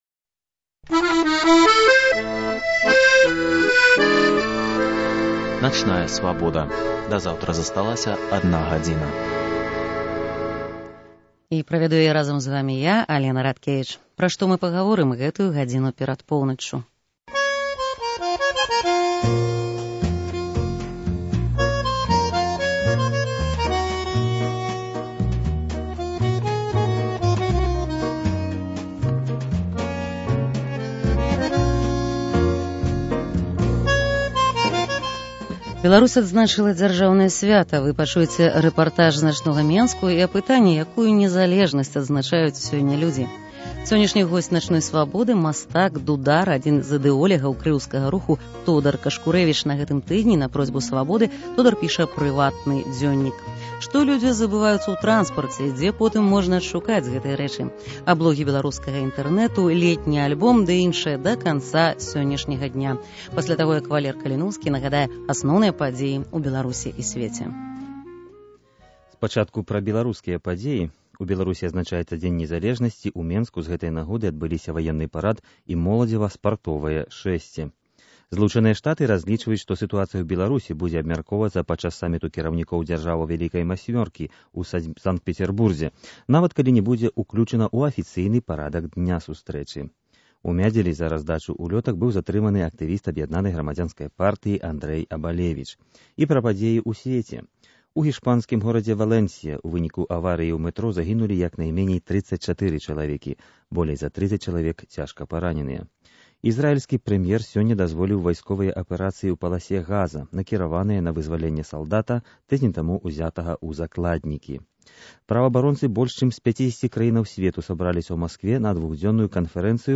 Рэпартаж з начнога Менску.